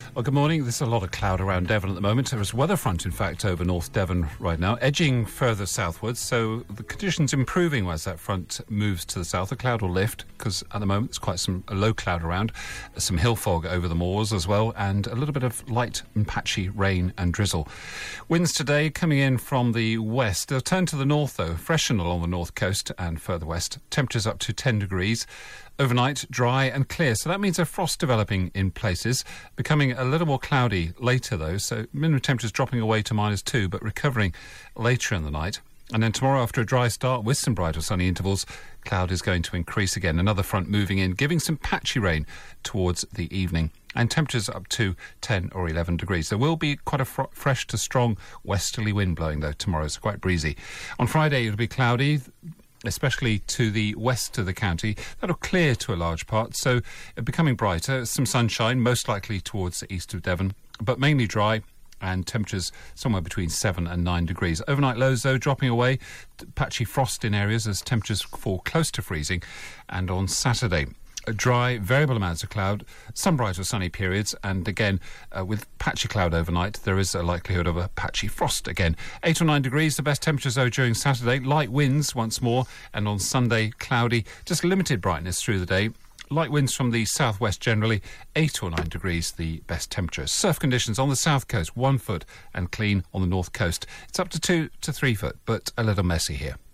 5 day forecast for Devon from 8.35AM on 4 December